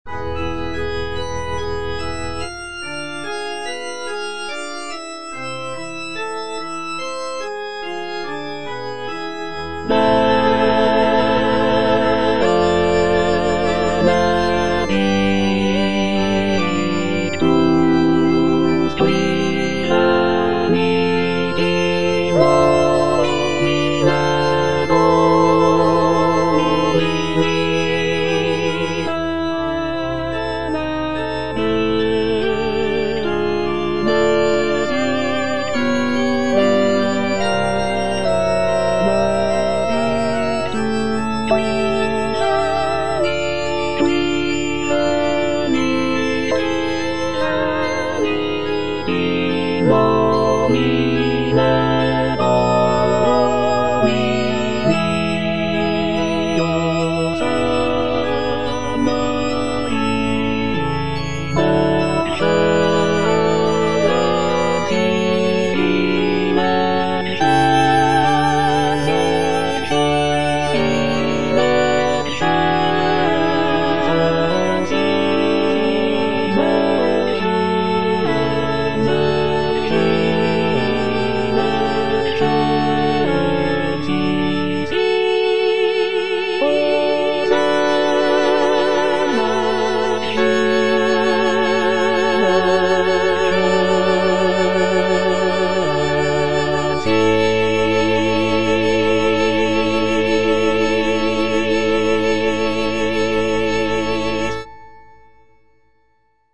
Choralplayer playing Missa Misericordias Domini op. 192 by J.G. Rheinberger based on the edition CPDL #25236
J.G. RHEINBERGER - MISSA MISERICORDIAS DOMINI OP.192 Benedictus (choral excerpt only) (All voices) Ads stop: auto-stop